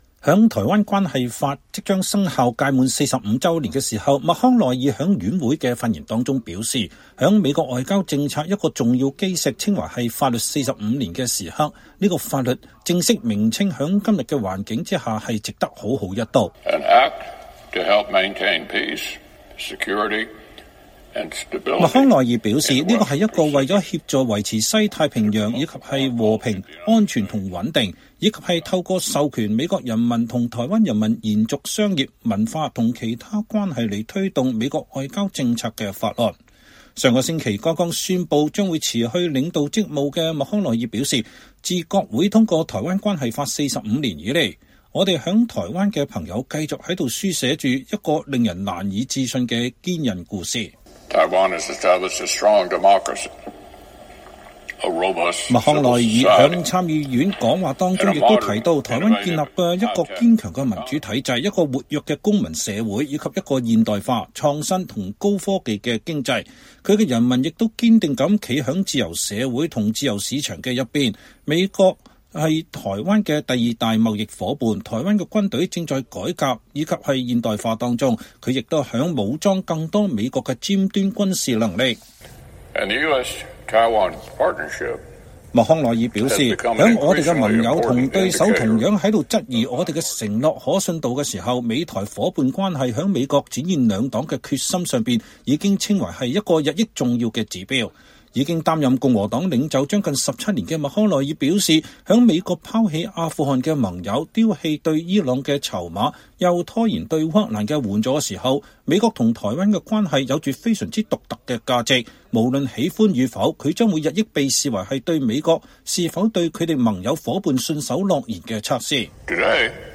美國參議院共和黨領袖米奇·麥康奈爾(Mitch McConnell)星期三(3月6日)在參議院院會上發表講話，紀念美國國會1979年通過的《台灣關係法》簽署成法將滿45週年。